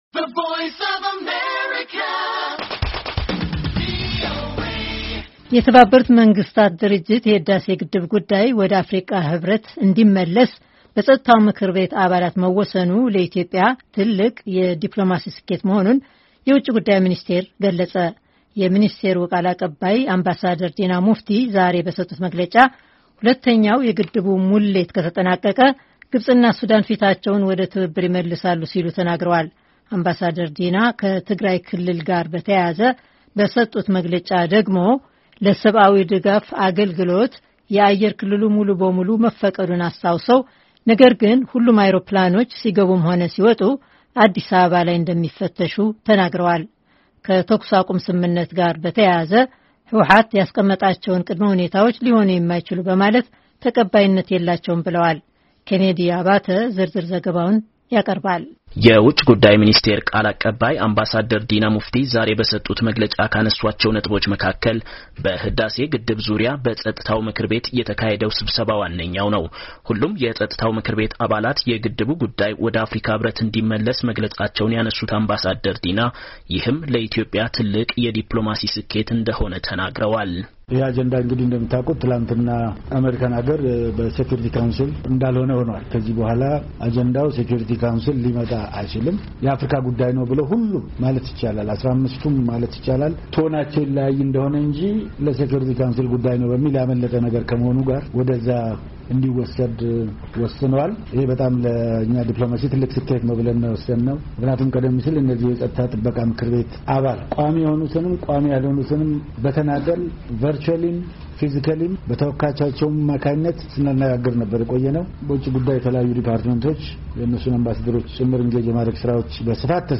የውጭ ጉዳይ ሚኒስቴር ቃል አቀባይ አምባሳደር ዲና ሙፍቲ
የሚኒስቴሩ ቃል አቀባይ አምባሳደር ዲና ሙፍቲ ዛሬ በሰጡት መግለጫ፣ ሁለተኛው የግድቡ ሙሌት ከተጠናቀቀ፣ ግብጽና ሱዳን ፊታቸውን ወደ ትብብር ይመልሳሉ ሲሉ ተናግረዋል፡፡